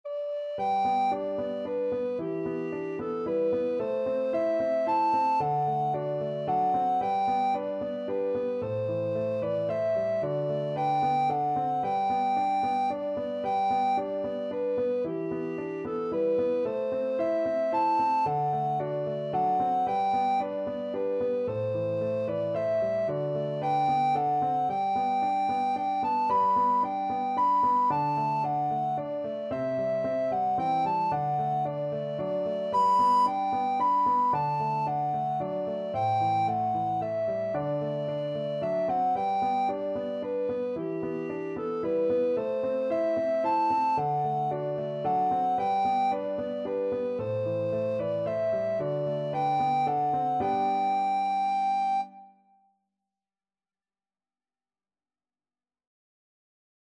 3/4 (View more 3/4 Music)
Moderately Fast ( = c. 112)
Traditional (View more Traditional Alto Recorder Music)